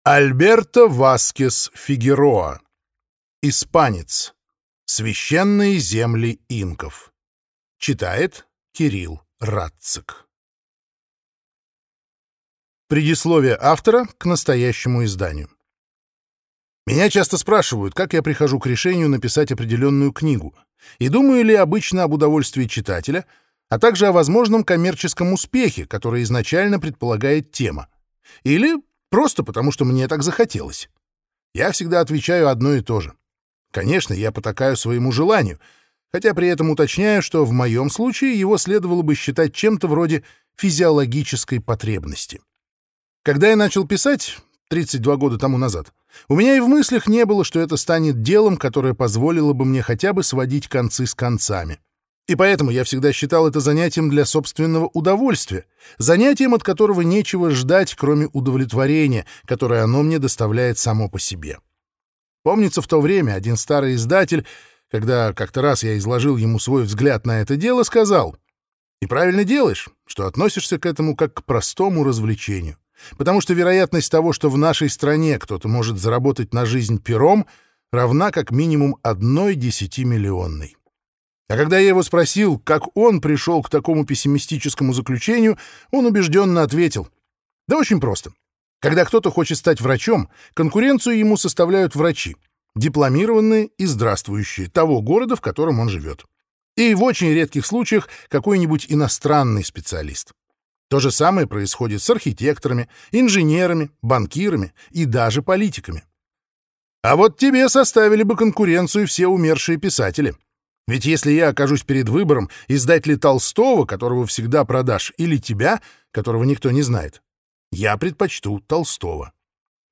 Аудиокнига Испанец. Священные земли Инков | Библиотека аудиокниг